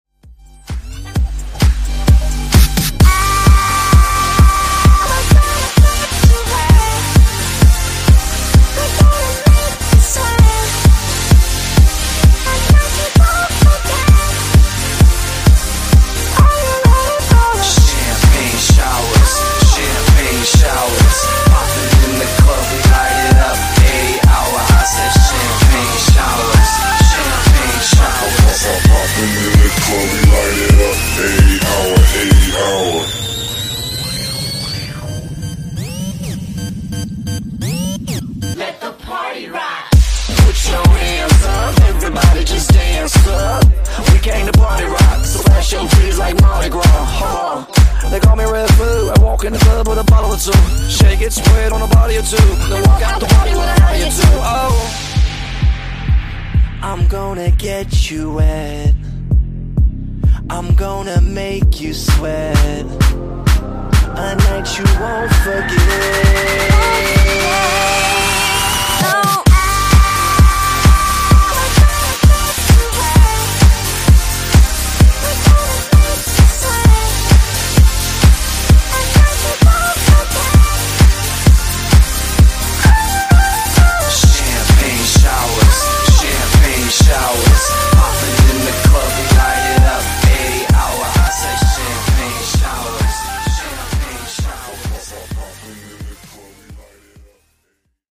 Genre: EDM
Dirty BPM: 128 Time